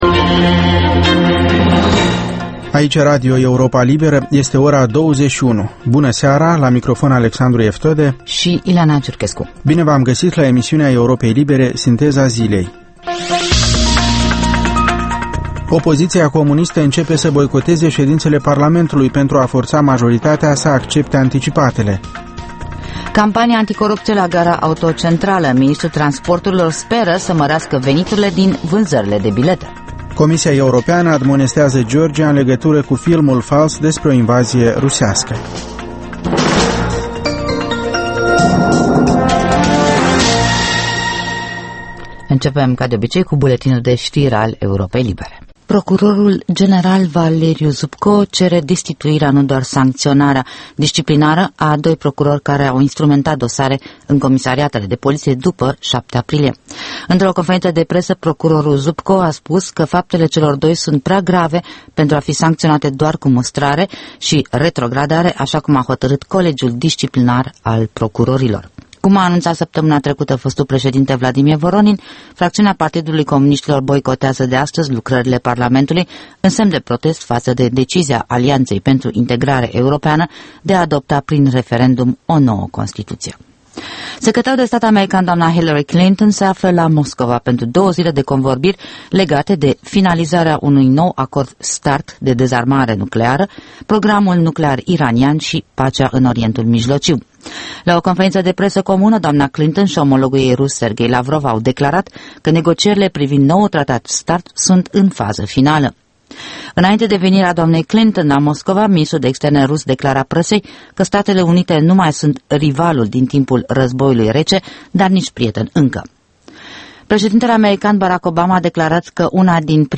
Programul de seară al Europei libere. Ştiri, interviuri, analize şi comentarii.